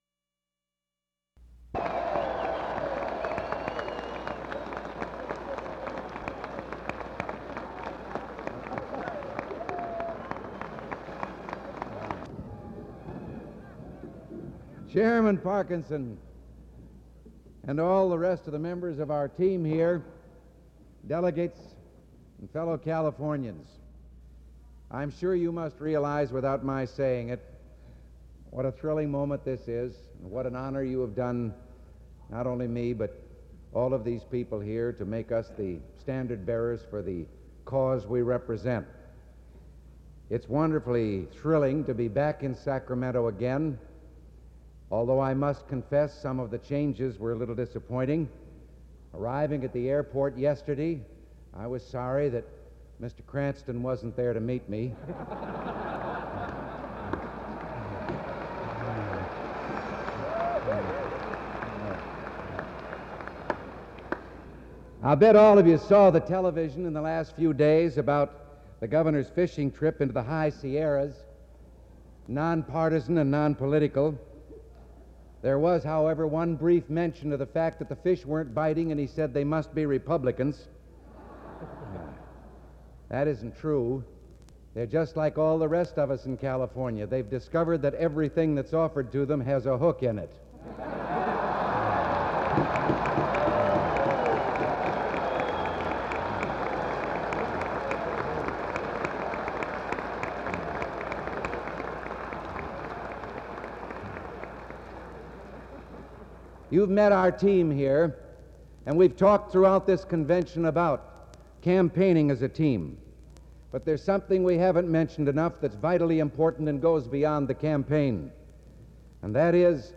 Ronald Reagan’s Speech at Republican State Convention, Sacramento, California